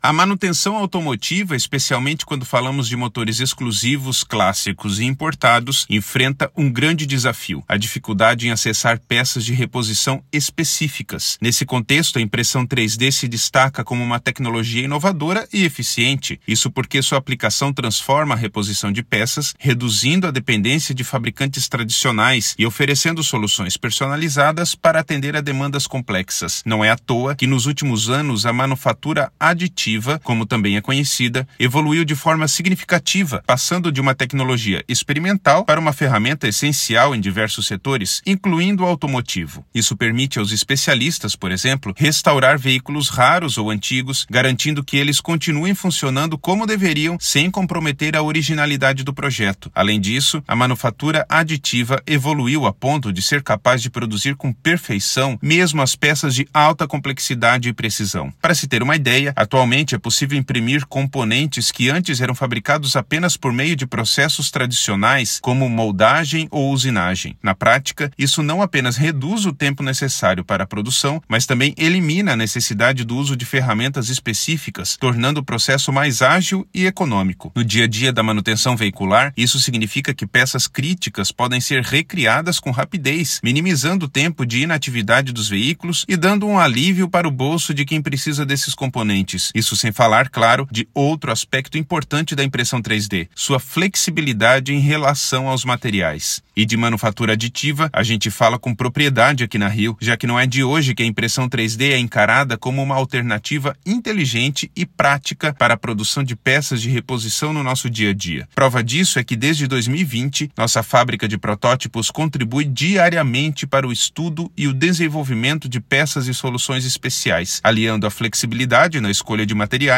Narracao-04-impressao-3D.mp3